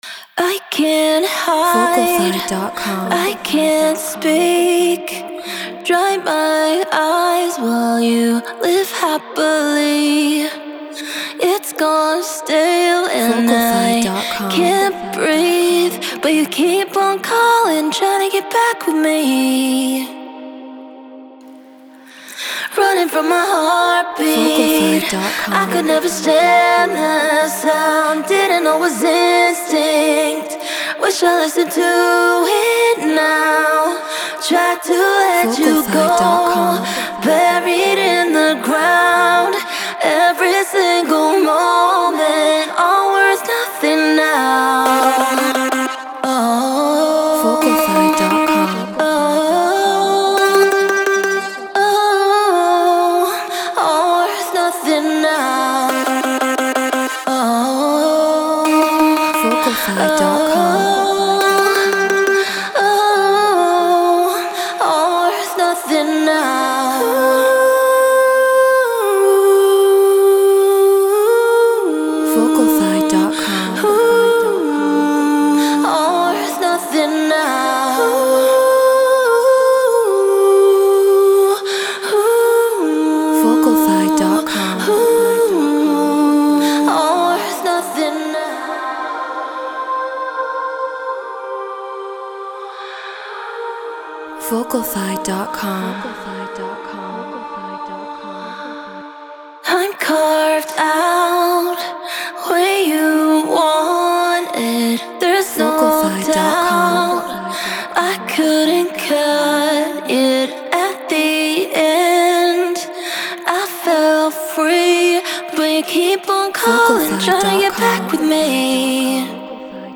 Future Bass 174 BPM G#maj
Human-Made